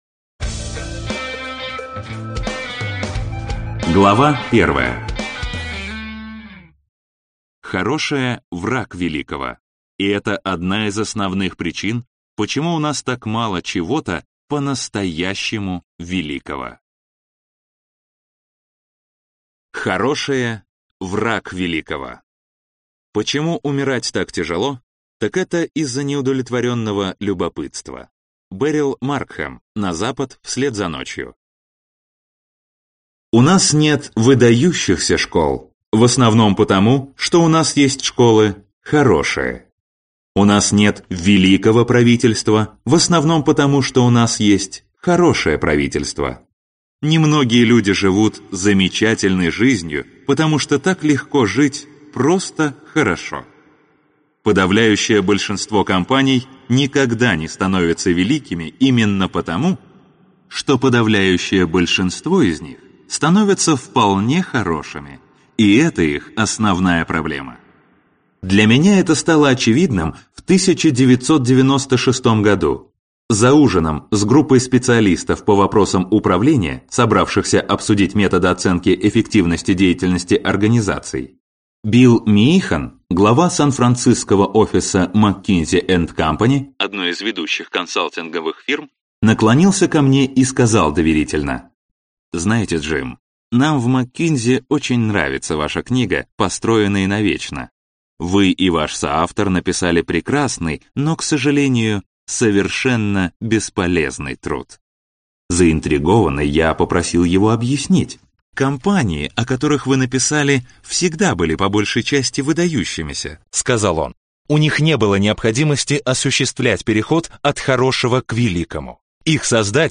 Аудиокнига От хорошего к великому - купить, скачать и слушать онлайн | КнигоПоиск